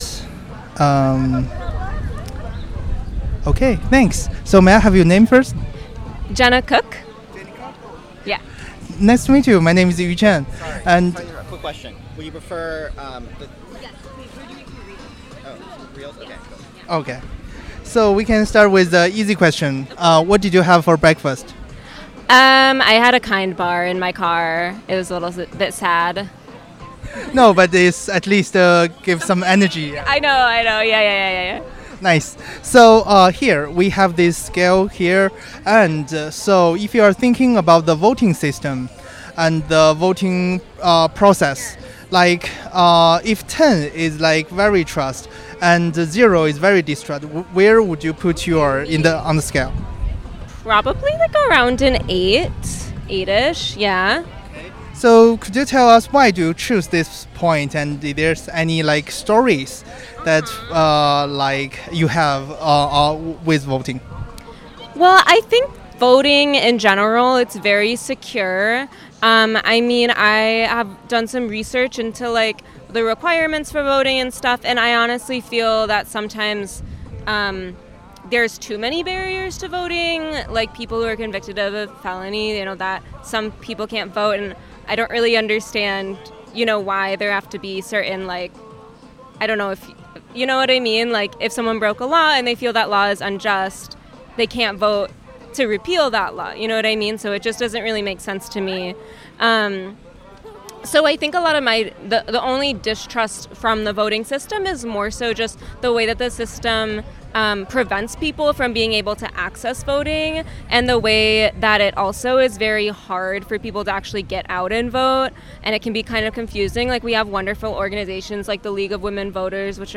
Interview
Location Despensa de la Paz